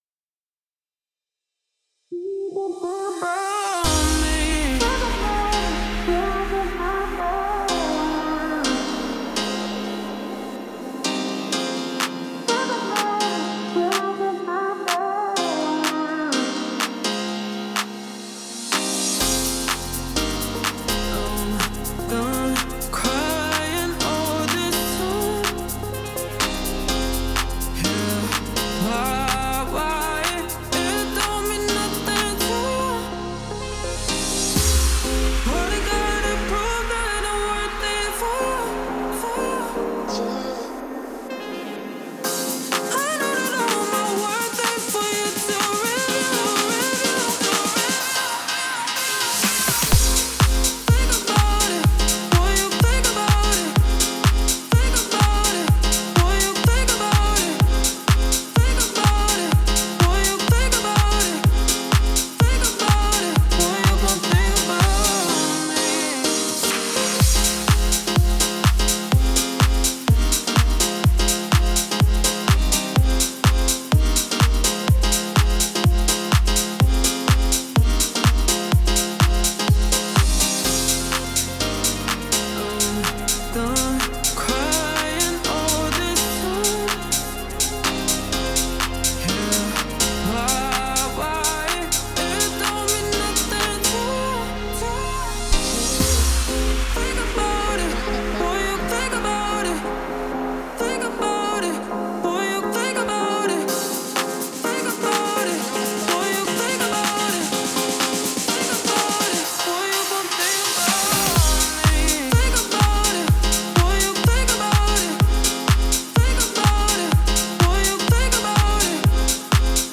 современная поп-песня